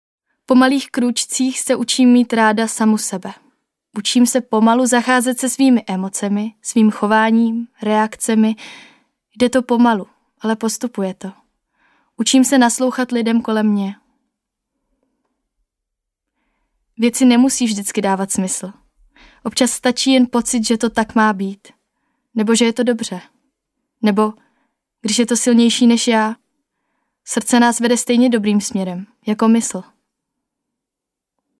Výpovědi k tématu emocí v komunikaci: